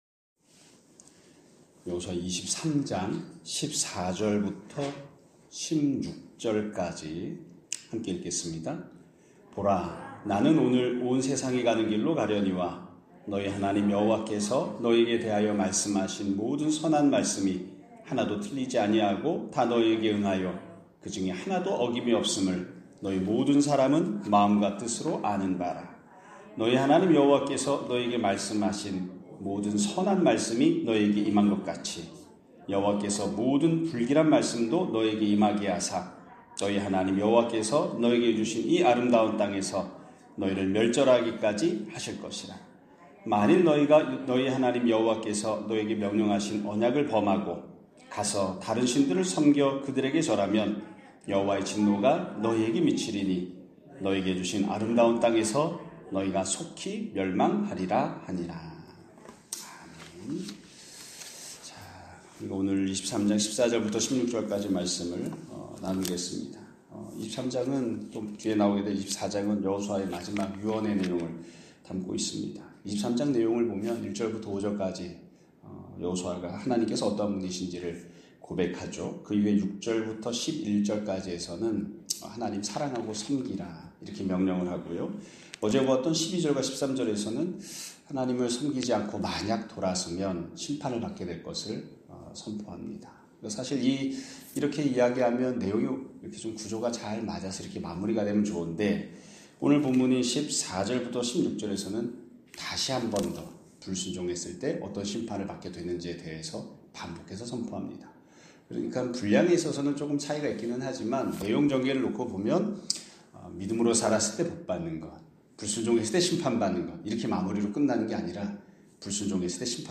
2025년 2월 20일(목요일) <아침예배> 설교입니다.